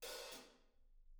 R_B Hi-Hat 06 - Room.wav